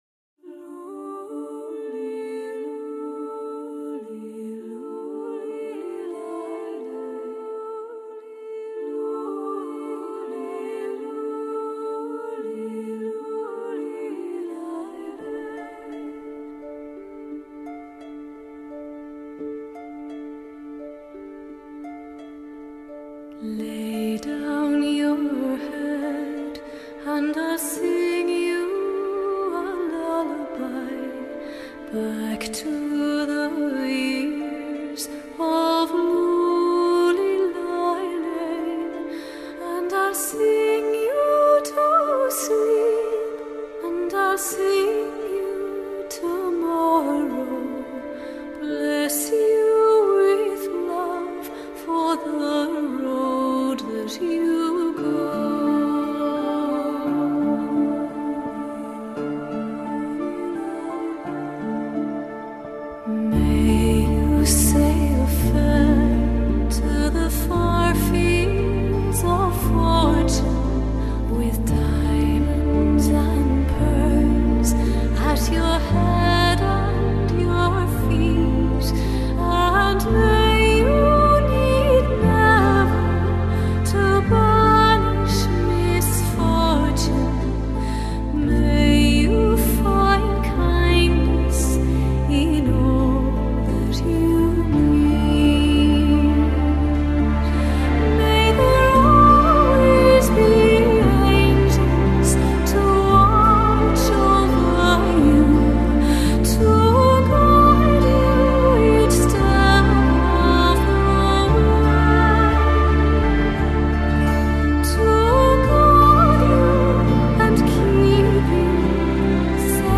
زیبا ترین شعری که یه زن خونده به نظرم.......